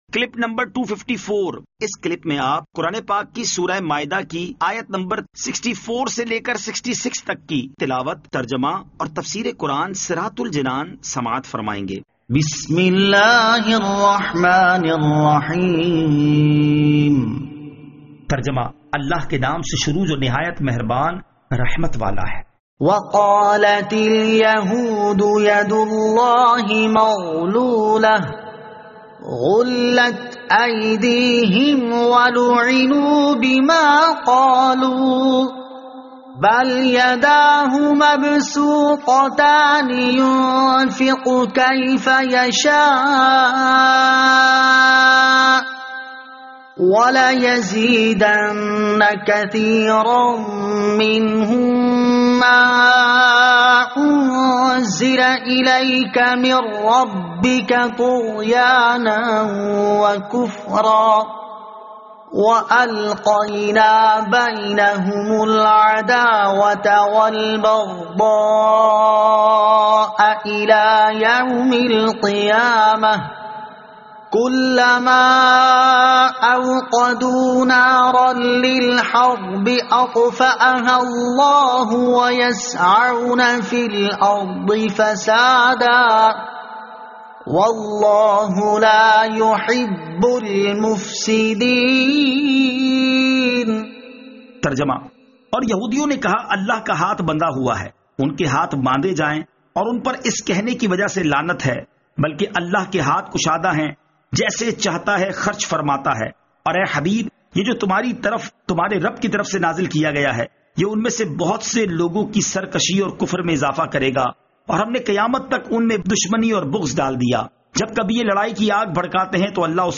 Surah Al-Maidah Ayat 64 To 66 Tilawat , Tarjama , Tafseer